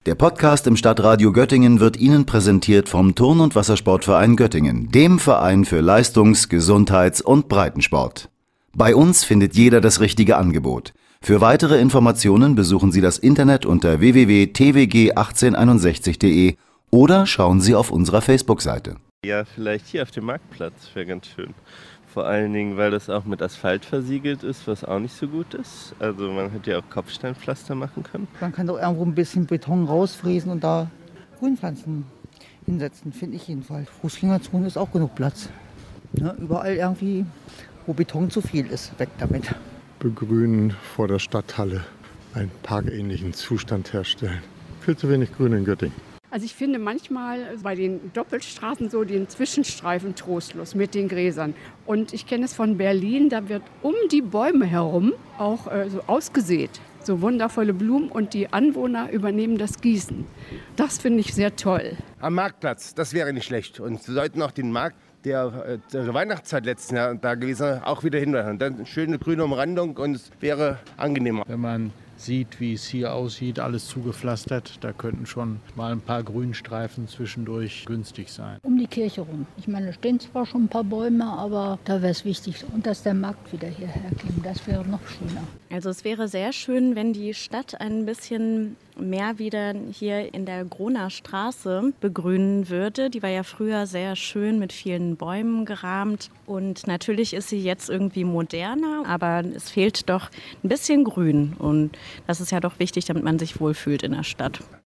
war in der Göttinger Innenstadt unterwegs und hat nachgefragt, was sich die Göttinger:innen wünschen.